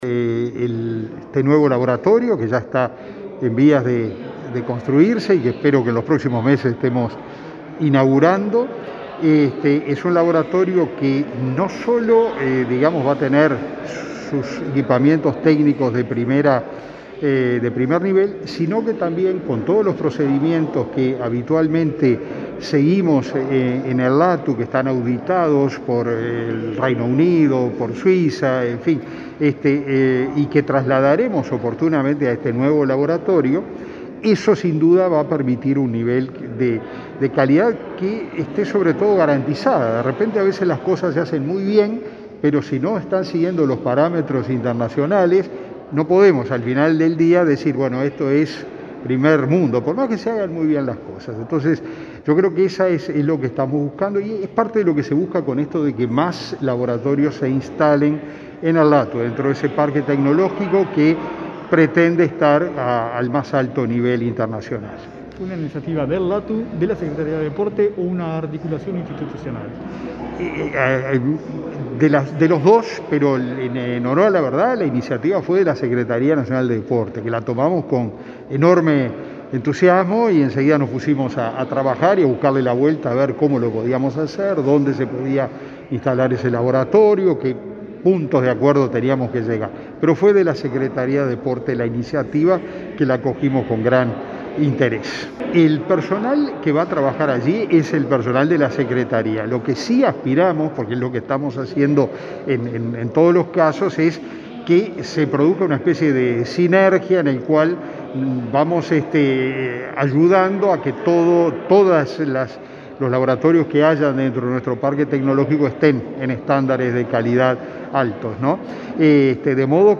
Declaraciones del presidente del LATU, Ruperto Long, sobre acuerdo de la SND y LATU por controles antidopaje